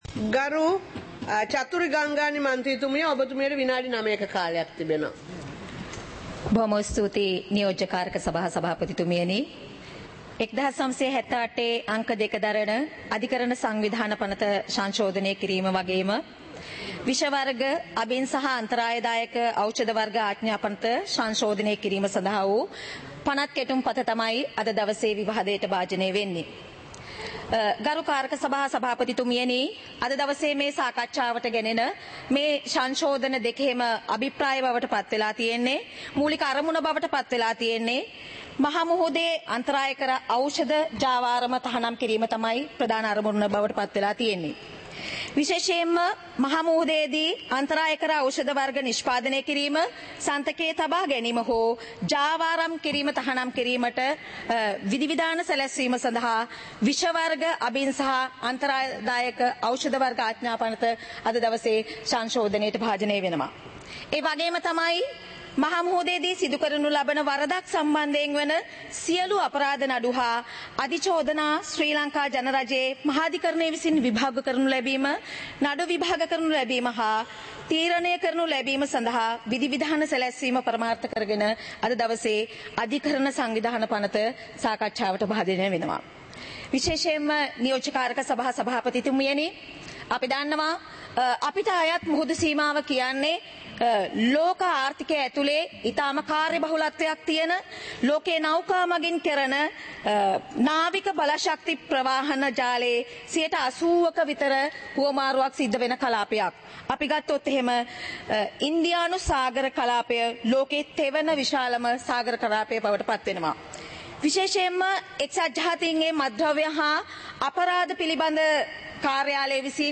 சபை நடவடிக்கைமுறை (2026-02-19)